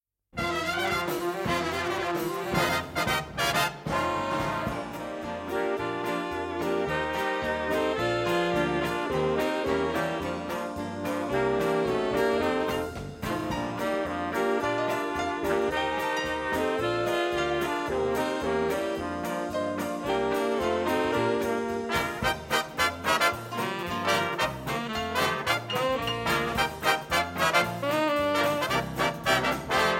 Audiokniha
Čte: Různí interpreti